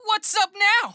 File:Pit voice sample EN SSB4.oga
Pit_voice_sample_EN_SSB4.oga.mp3